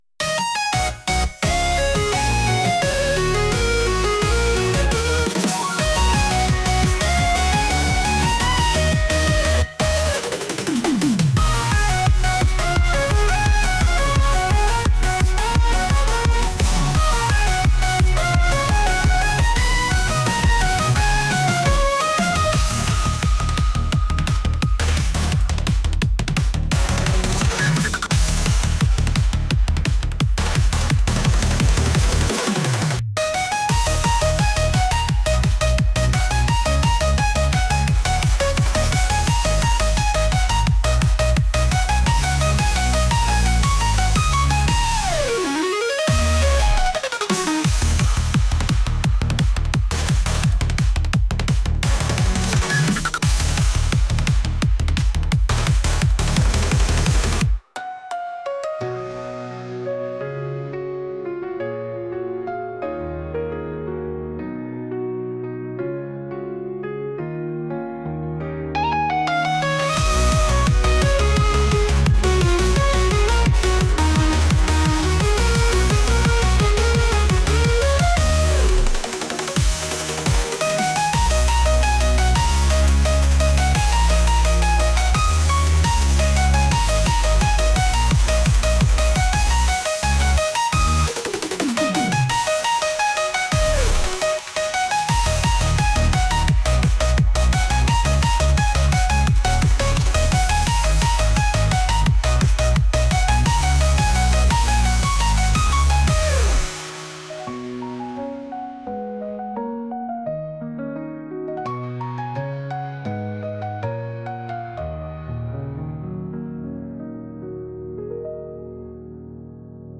戦闘風BGM
[Instrumental]
edm electronic